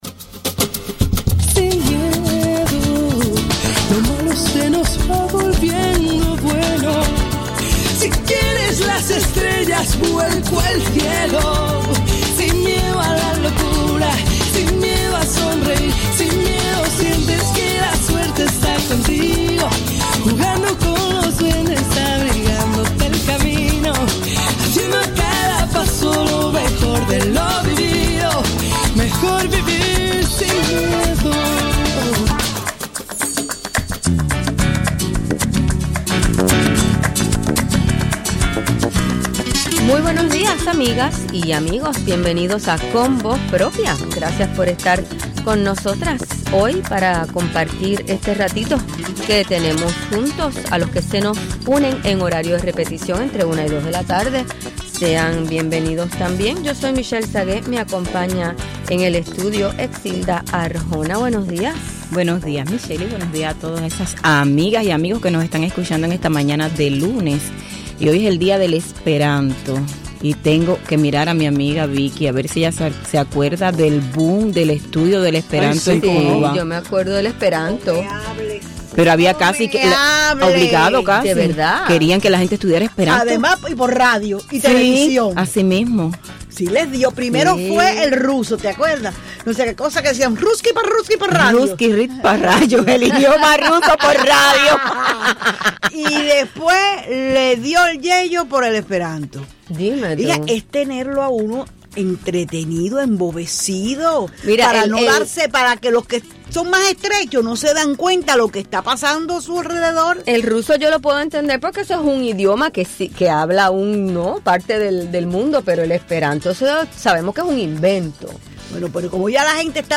También recibimos las llamadas de varios oyentes.